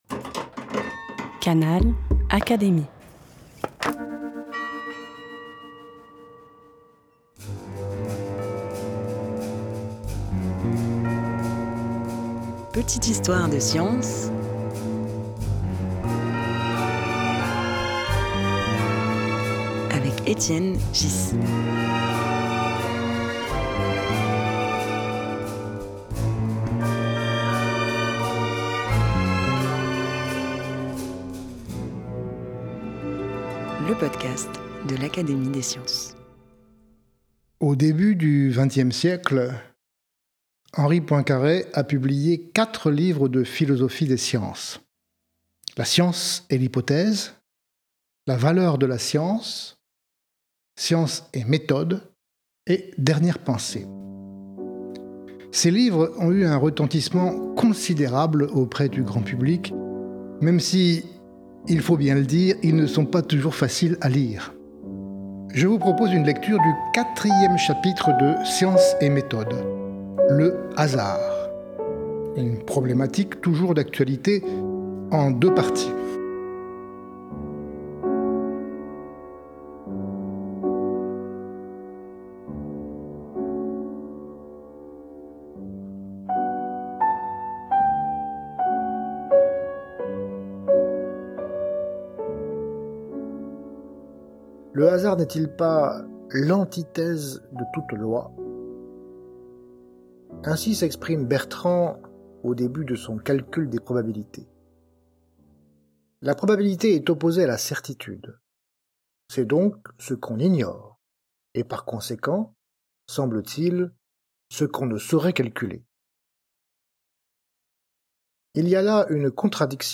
Extrait lu par Étienne Ghys, Secrétaire perpétuel de l’Académie des sciences, tiré du livre Science et Méthode, par Henri Poincaré (1908).